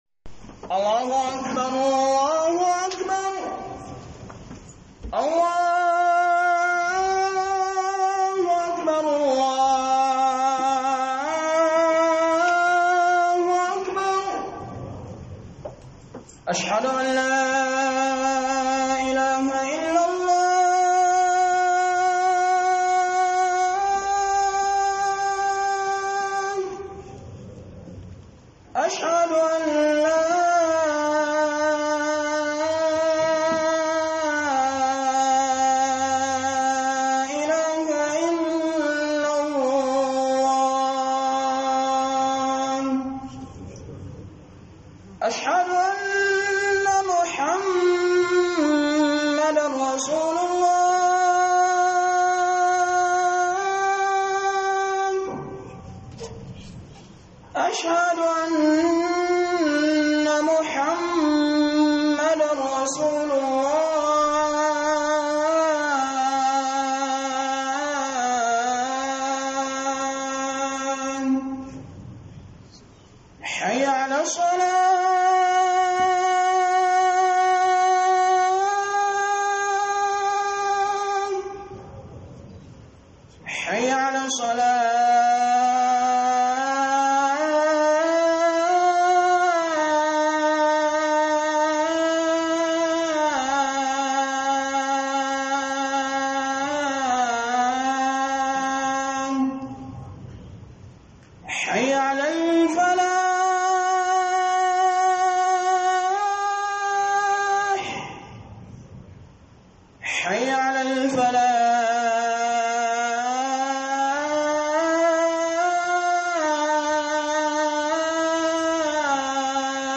AIKIN HAJJI - Huduba